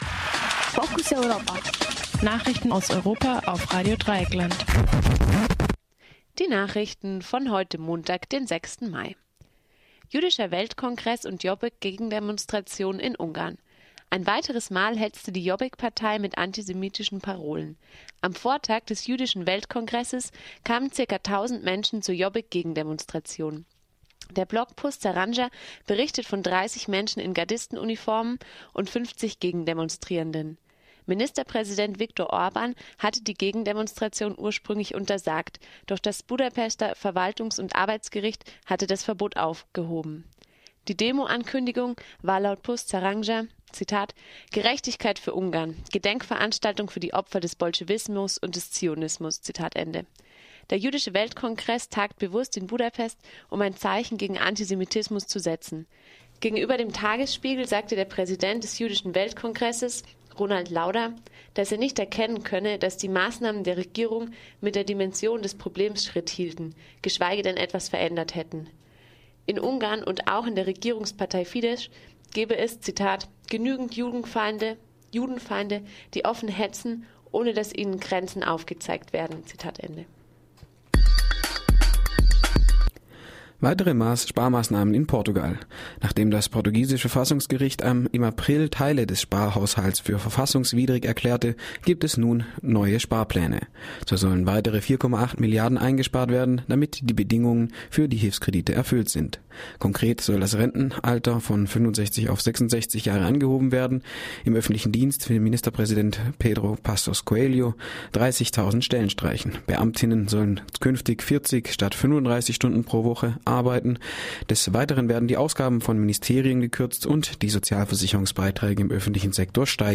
Focus Europa Nachrichten von Montag, den 6. Mai - 9:30 Uhr